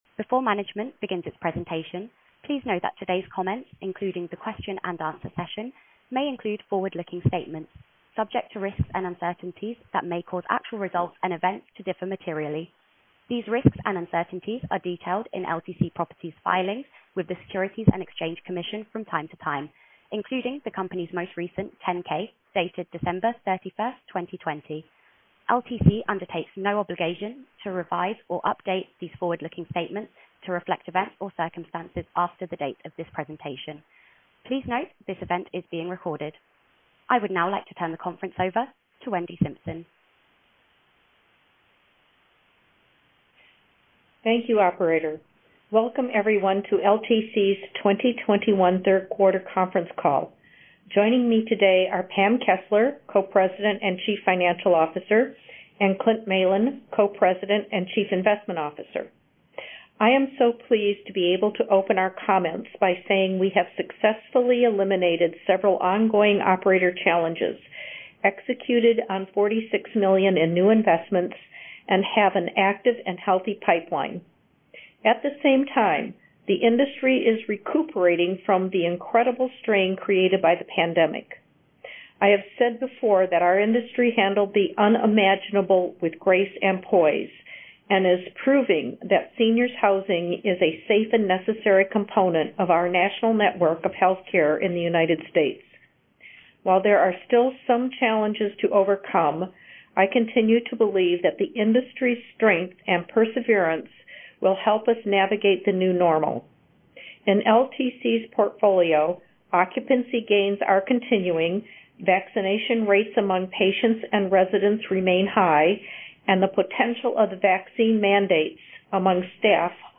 Earnings Webcast Q3 2021 Audio
LTC-3Q21-Analyst-and-Investor-Call.mp3